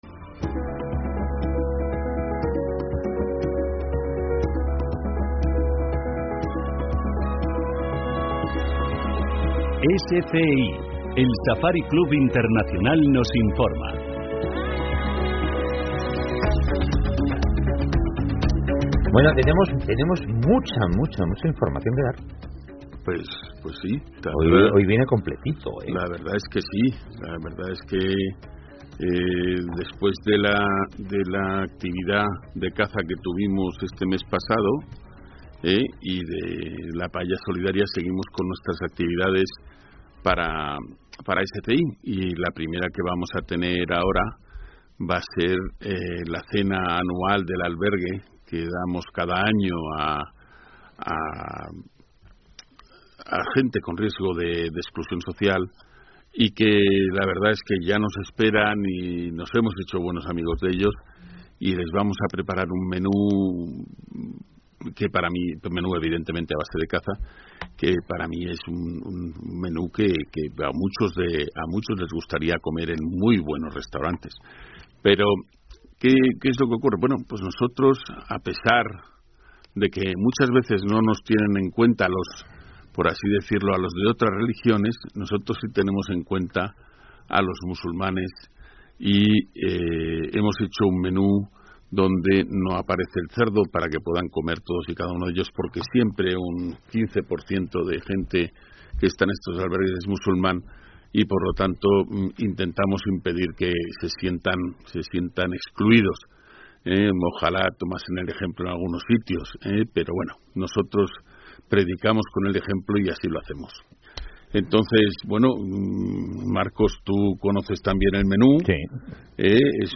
El programa de radio del Safari Club Internacional (SCI) ha ofrecido un panorama de sus próximas actividades, fusionando la acción social con la defensa de la actividad cinegética y el debate sobre la gestión de la fauna.